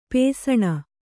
♪ pēsaṇa